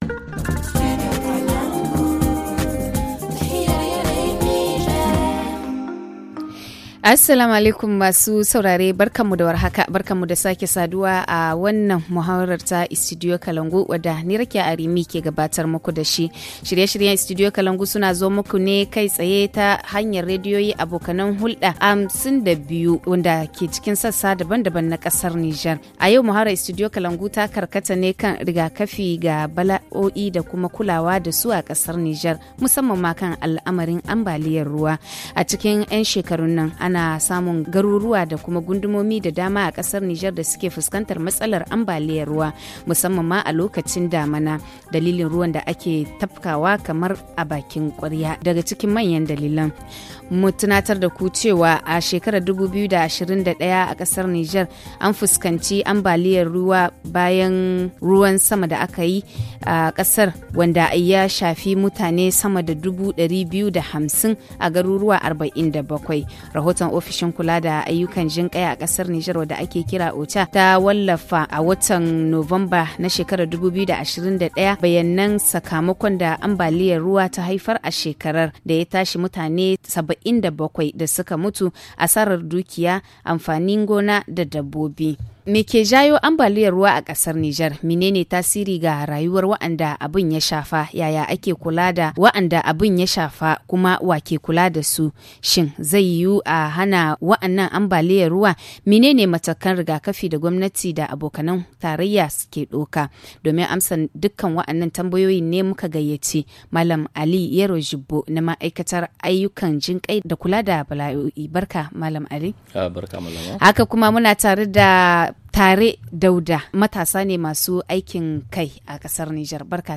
HA Le forum en haoussa https